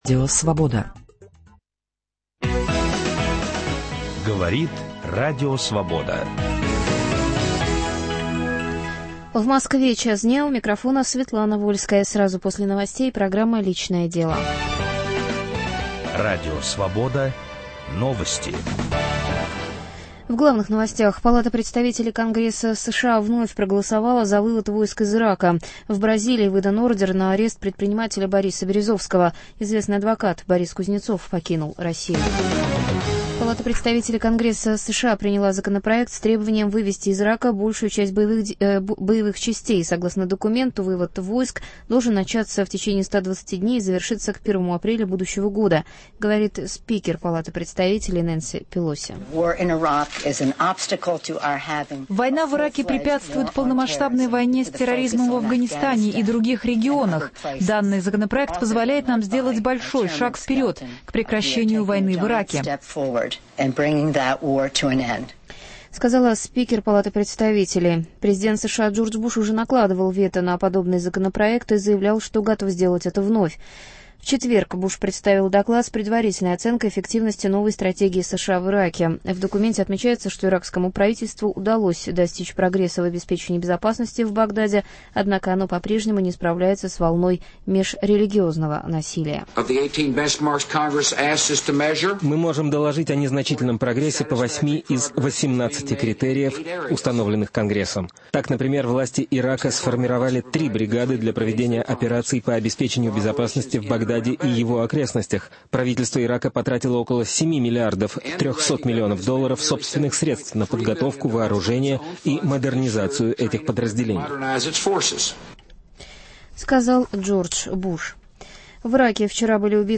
На эфир приглашены победитель последнего ММКФ режиссер Вера Сторожева; сценарист, писатель Алексей Слаповский